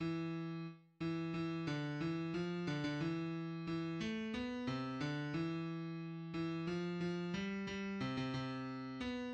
{\clef bass \tempo 4=90 \time 2/4 \set Score.currentBarNumber = #1 \bar "" e4 r8 e8 e d e f d16 d e4 e8 a b c d e4. e8 f f g g c16 c c4 b8 }\addlyrics {\set fontSize = #-2 - Di ar- bet traybt mikh fri a- roys Un lozt mikh shpet tsu- rik A fremd iz mir mayn ey- gn leyb!